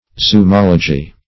\Zu*mol"o*gy\, n., Zumometer \Zu*mom"e*ter\, n., etc.